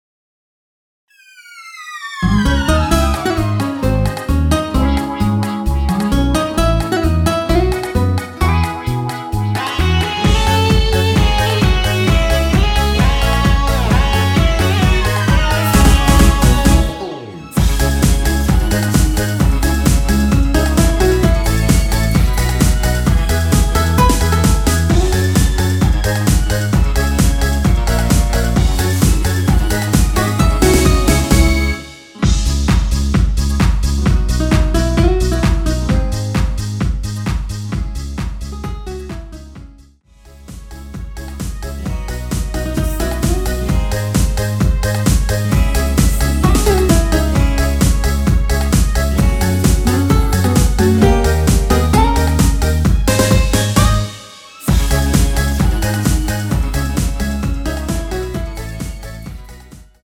원키 코러스 포함된 MR입니다.
C#m
앞부분30초, 뒷부분30초씩 편집해서 올려 드리고 있습니다.
중간에 음이 끈어지고 다시 나오는 이유는